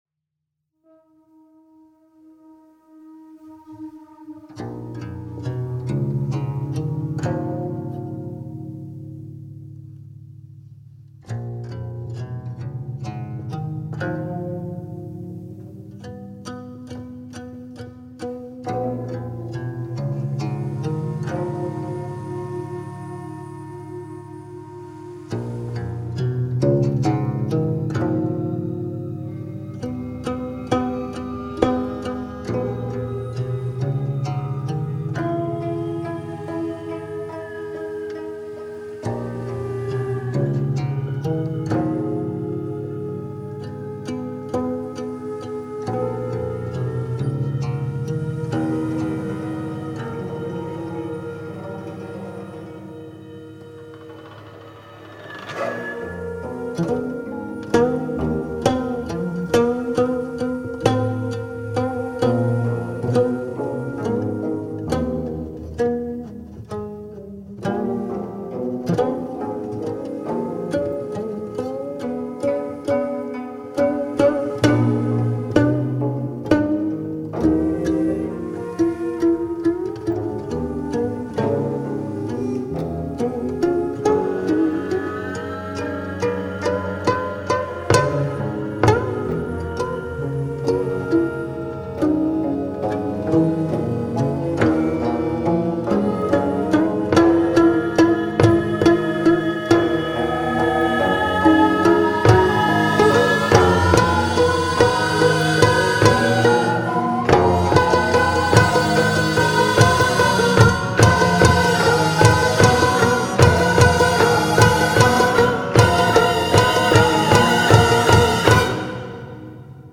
concerto for 9-string geomungo & gugak orchestra